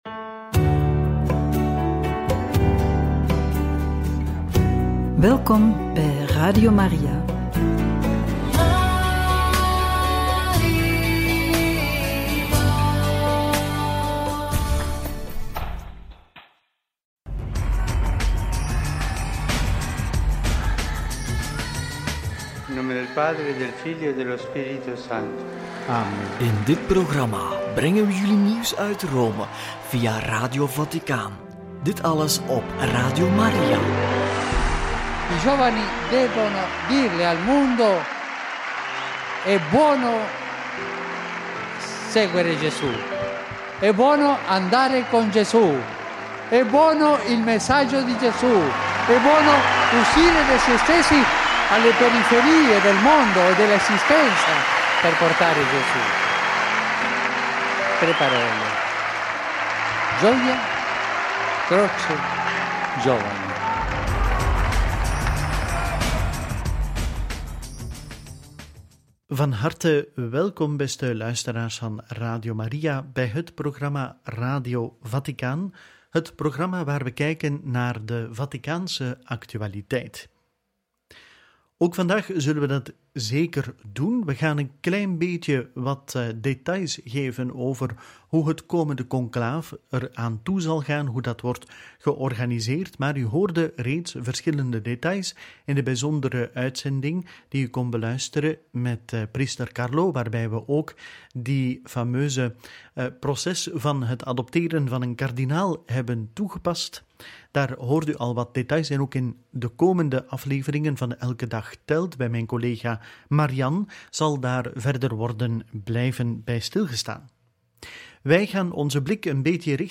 Novemdiales – Luister naar de homiliën van kardinalen Leonardo Sandri en Victor Manuel Fernandez – Details conclaaf – Radio Maria